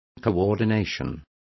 Complete with pronunciation of the translation of coordination.